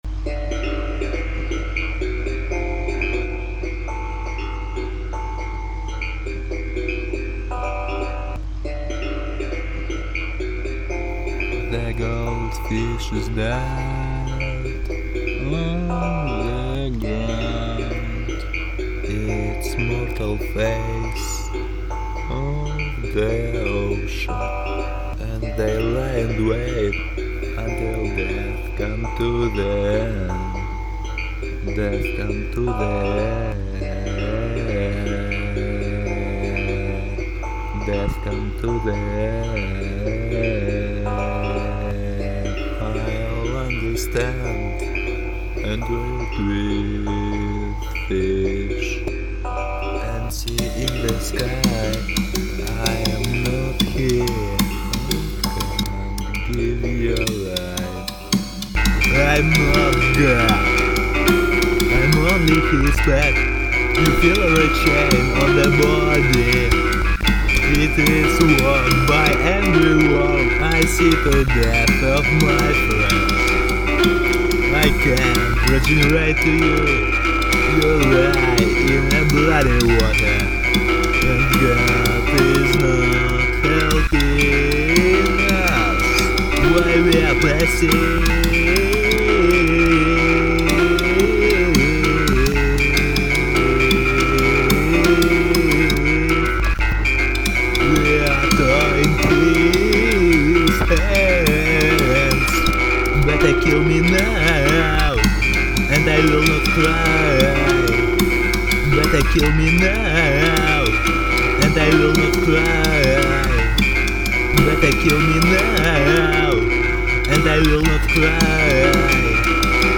Альтернативный рок